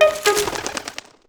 MailSent.wav